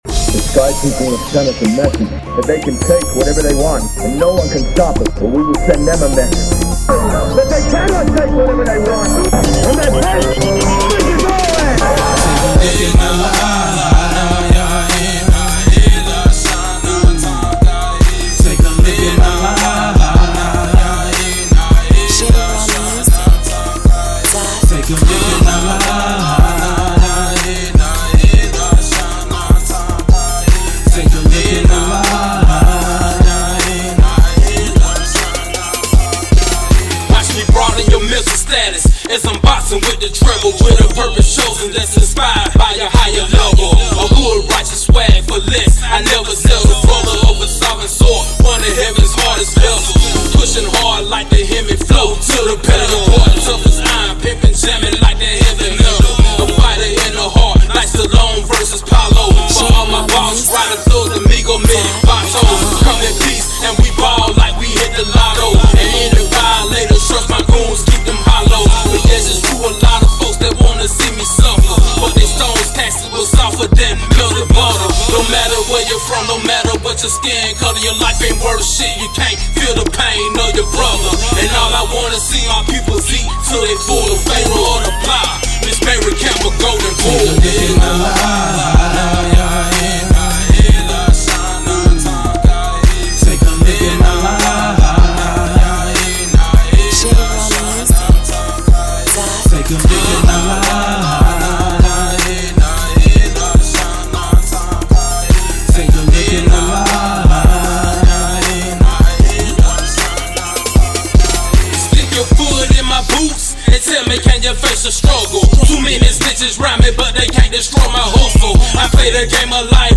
Genre: Gangsta Rap.